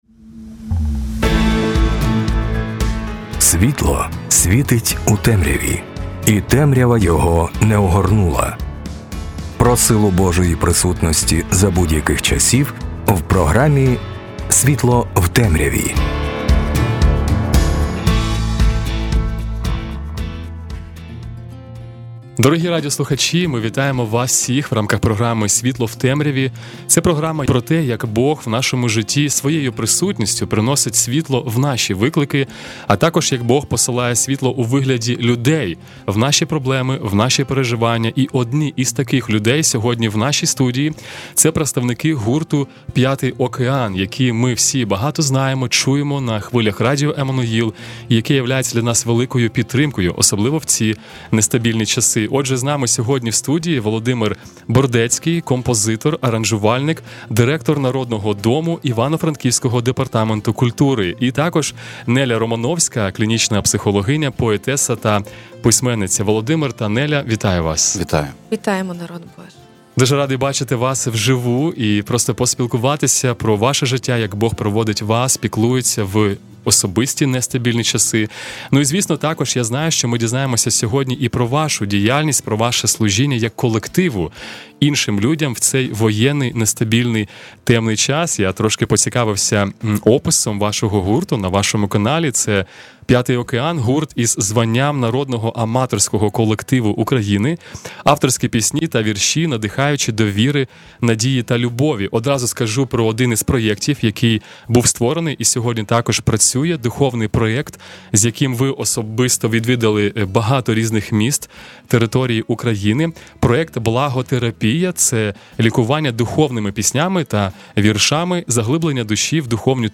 Авторські пісні та вірші, надихаючі до віри, надії та любові.